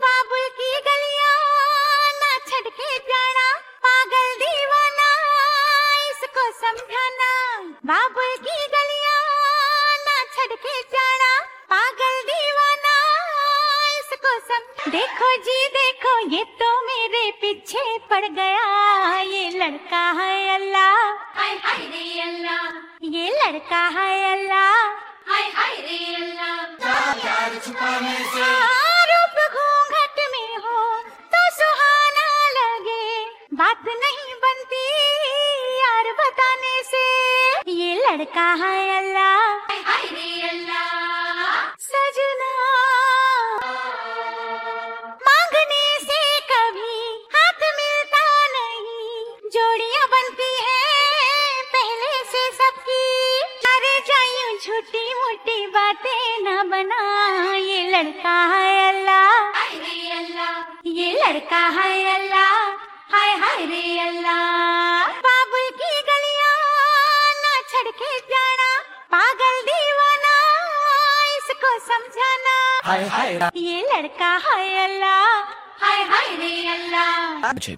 Bollywood Song